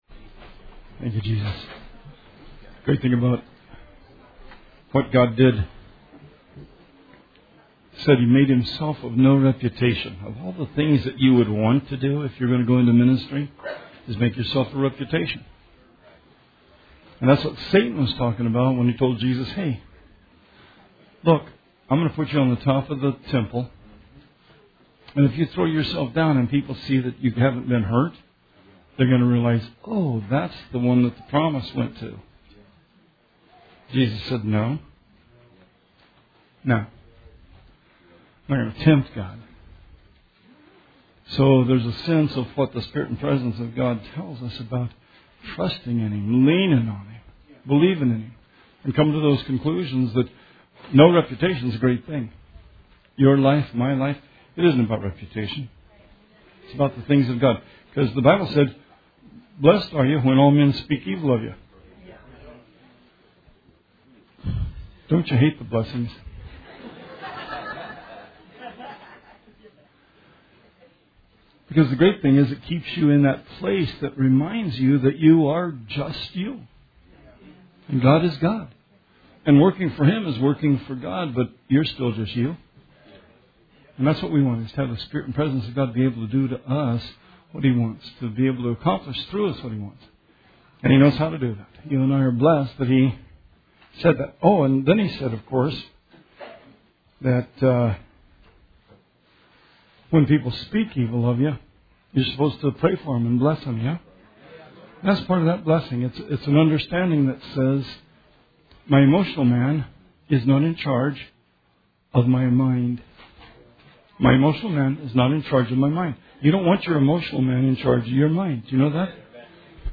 Sermon 2/25/18